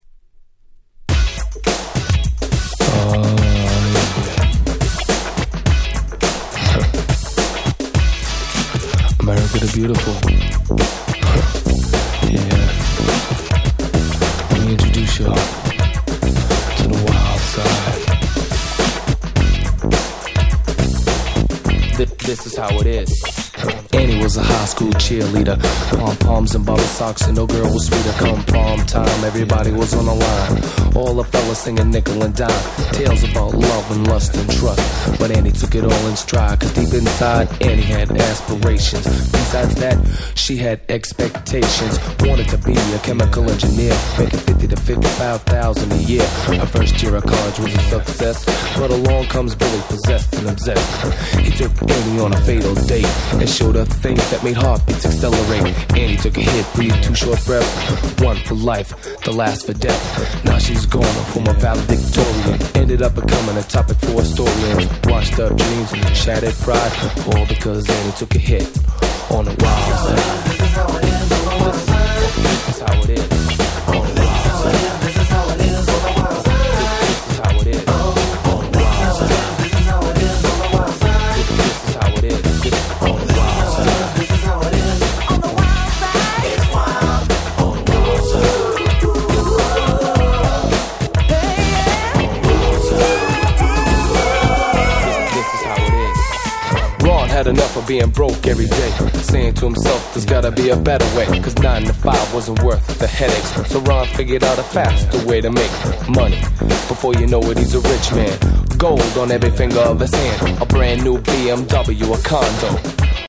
91' Super Hit Hip Hop !!